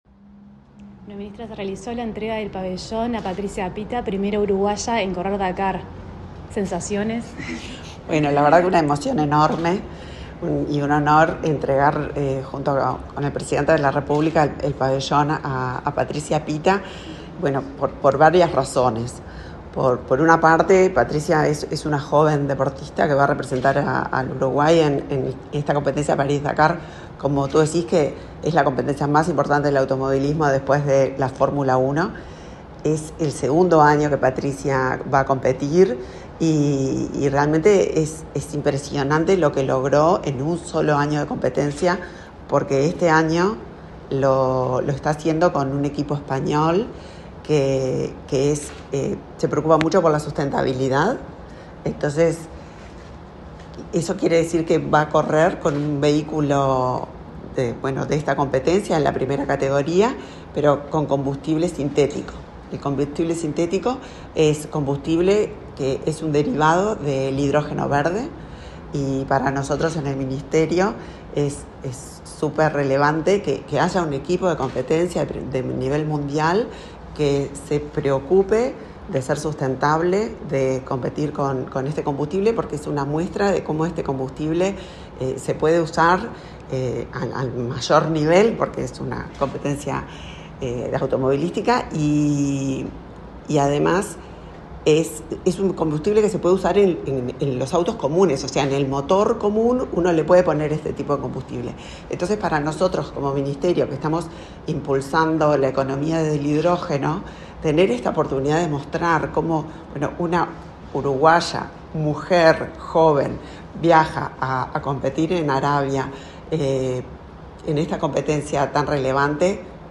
Entrevista a la ministra de Industria, Elisa Facio
La ministra de Industria, Elisa Facio, dialogó con Comunicación Presidencial en la Torre Ejecutiva, luego de que el presidente Luis Lacalle Pou le